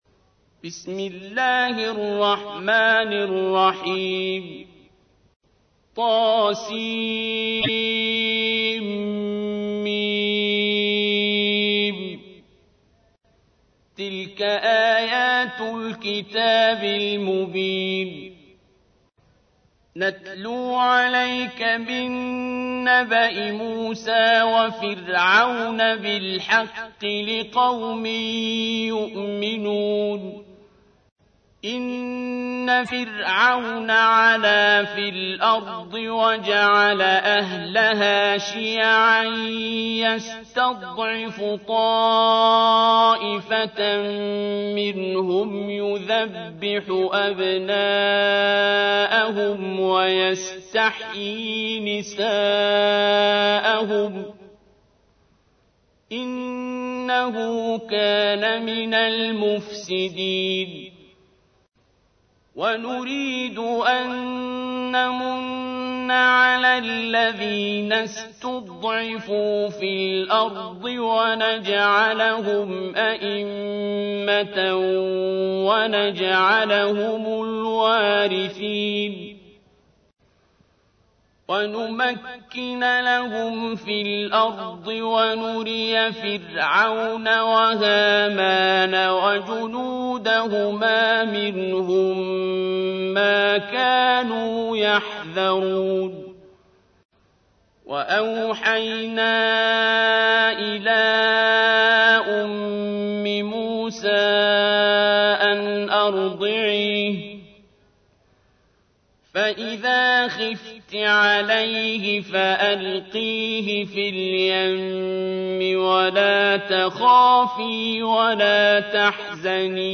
تحميل : 28. سورة القصص / القارئ عبد الباسط عبد الصمد / القرآن الكريم / موقع يا حسين